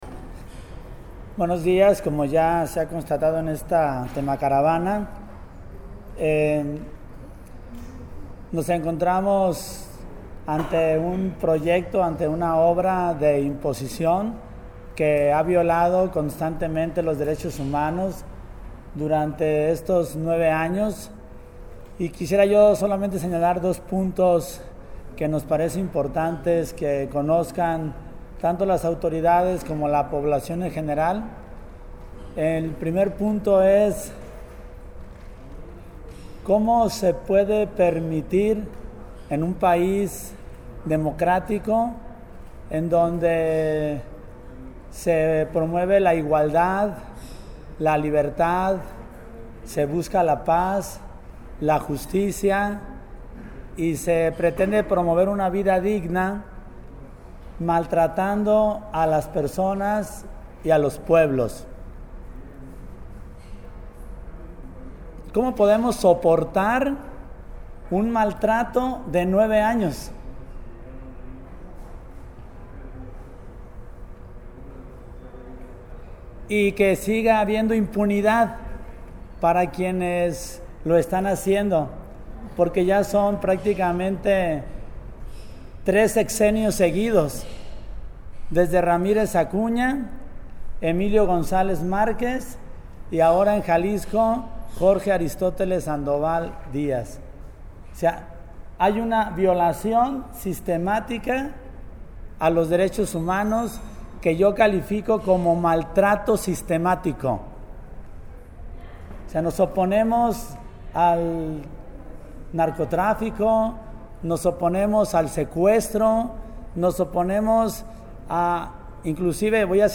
En conferencia de prensa se dio a conocer el resultado de la Auditoría popular a la presa el Zapotillo que afectará a las comunidades de Temacapulin, Palmarejo y Acasico en la que se señala la violación sistemática a los Derechos Humanos, las irregularidades en la construcción de la presa el Zapotillo, el posible desacato de la sentencia emitida por la Suprema Corte de Justicia de la Nación (SCJN), el mal manejo del agua en el estado de Jalisco y se exigió al presidente Enrique Peña Nieto cumplir los derechos de los pueblos originarios dejando de lado la política extractivista que tiene consecuencias irreparables al medio ambiente.